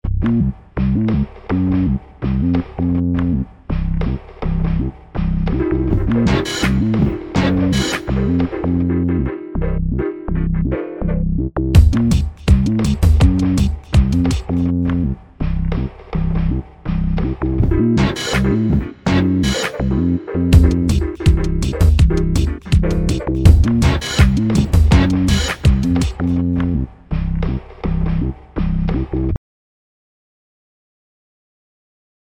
He created a bunch of loops that you can use in your own compositions if you want.